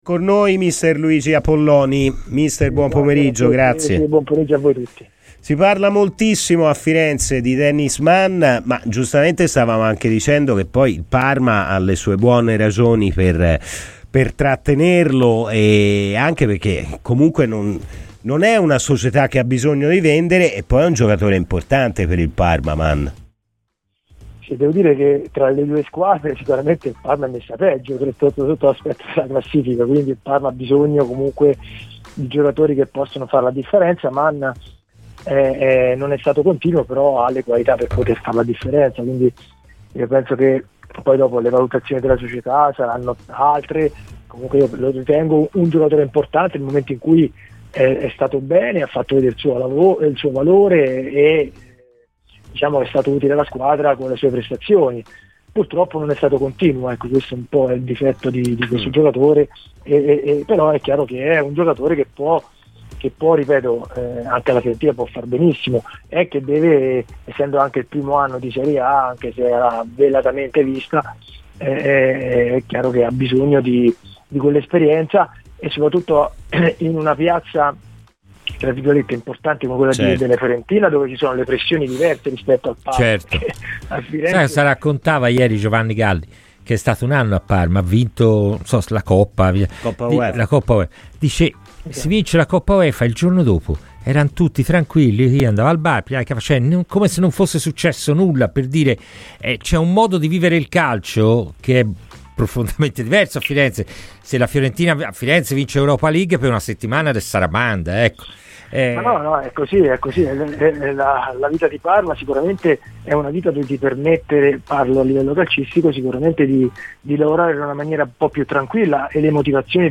L'ex calciatore oggi allenatore Luigi Apolloni è intervenuto a Radio FirenzeViola durante la trasmissione "Palla al centro" per parlare dell'attualità di casa Fiorentina.